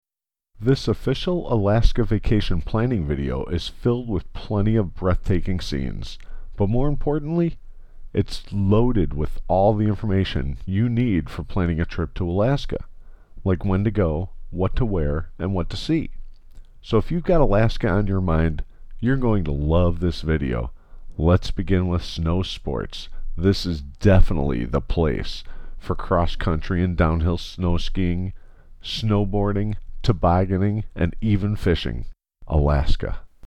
Straight Focus Design near Chicago offers voice over work and a voice artist
Alaska Vacation Demo - > :33 seconds